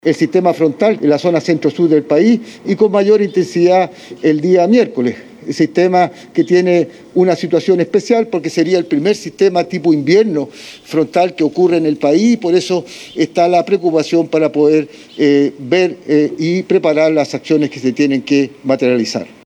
Frente a este pronóstico, desde la Onemi, su director Ricardo Toro, informó que se preparan para enfrentar eventuales emergencias.